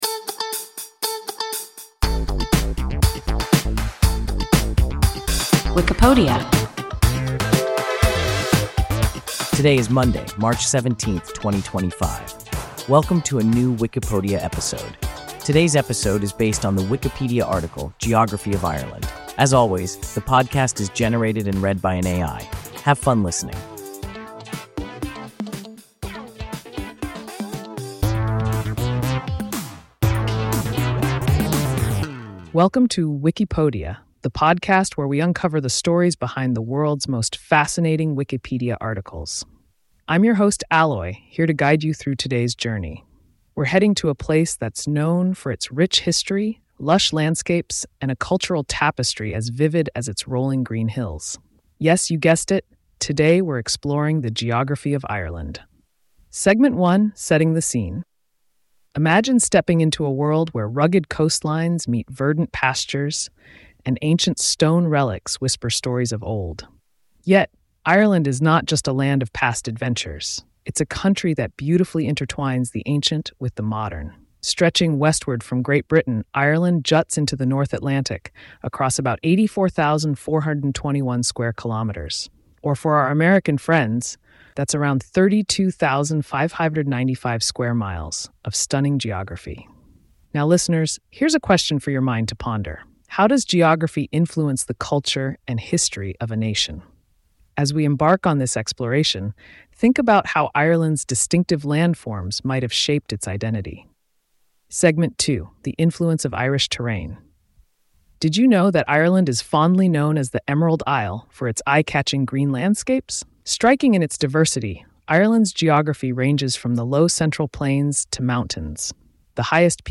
Geography of Ireland – WIKIPODIA – ein KI Podcast